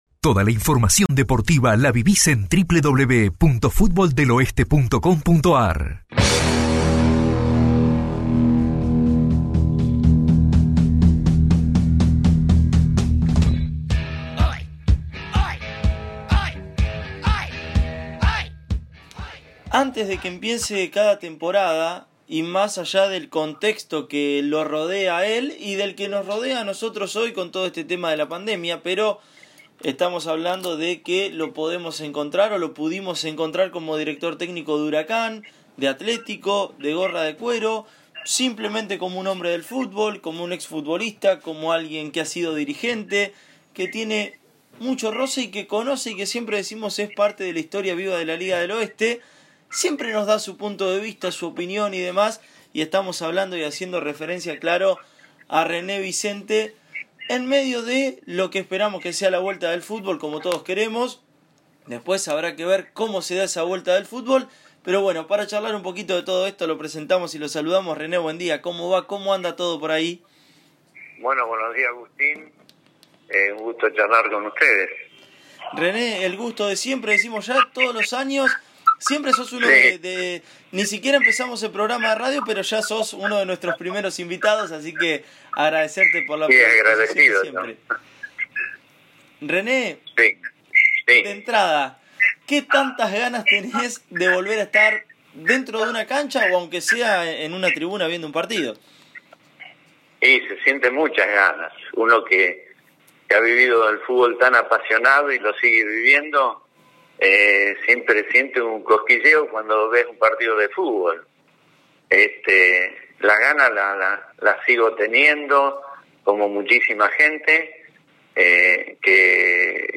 El experimentado entrenador, quien además tuvo su época como jugador y también como dirigente, habló con Fútbol del Oeste sobre el posible regreso de los torneos oficiales de la Liga del Oeste dando su opinión al respecto.